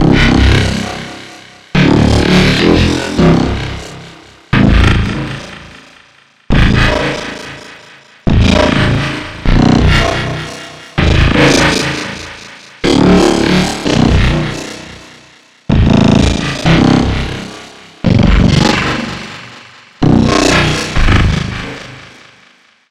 Reminiscent of Akai time-stretching.